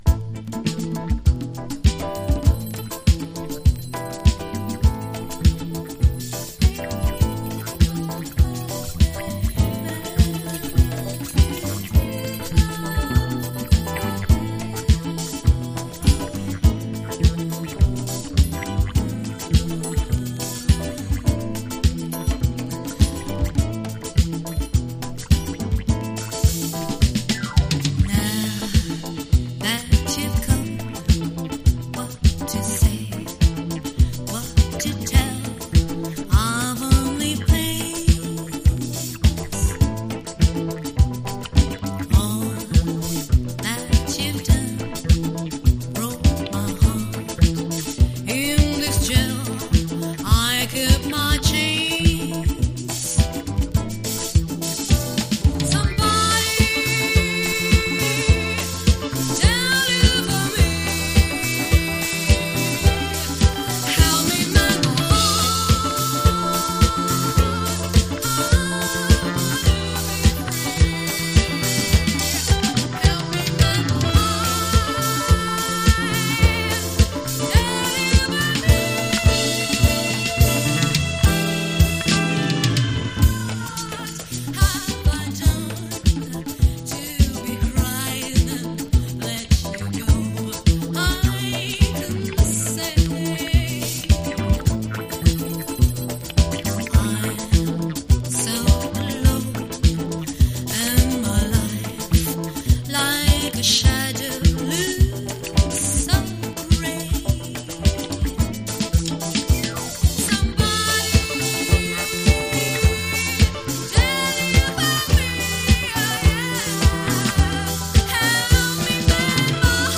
a splendid bewitching Island Soul track from Guadeloupe
with Jazz flavors
Keyboards
Percussion
Drums
Bass, Vocals
Guitar